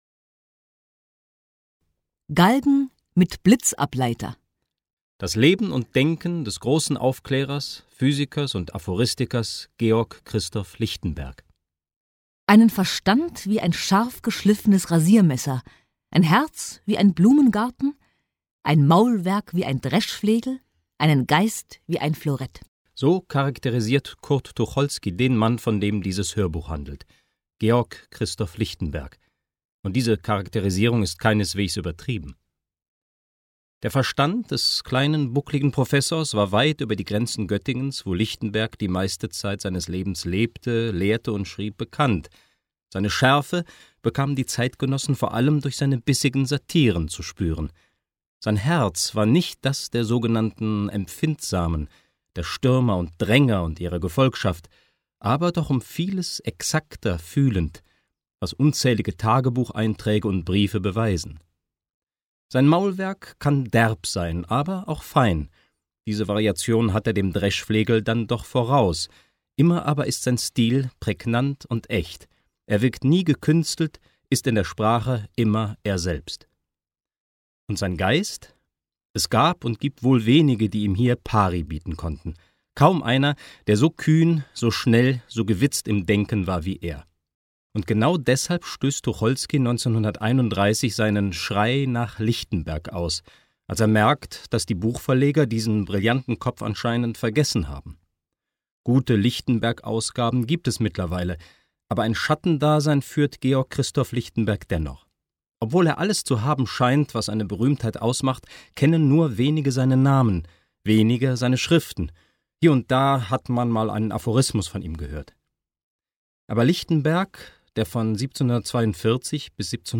Lichtenbergs sprühender Witz kann in einem Hörbuch nicht in der Aneinanderreihung seiner brillanten Aphorismen vermittelt werden; diese müssen eingebettet sein in eine “Geschichte” – was liegt also näher, als die Lebensgeschichte Lichtenbergs mit seinen Aphorismen zu verbinden? Dieses Hörbuch gibt also einen Einblick in das Leben des großartigen kleinwüchsigen Mannes, lässt aber zugleich die geistige Elastizität, die aufgeklärte Haltung und die Resultate seines freien Selbstdenkens in Originalzitaten zu Wort kommen.